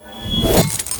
bsword2.ogg